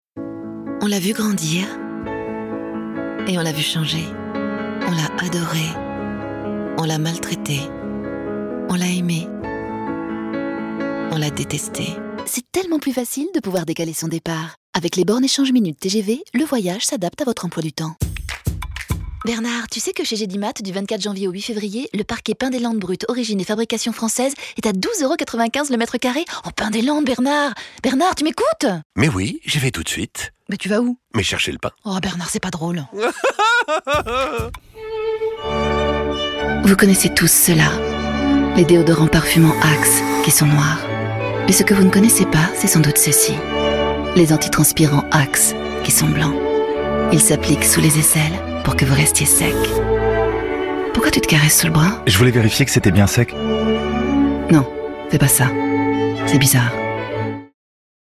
chaleureuse | complice | droite | douce | envoûtante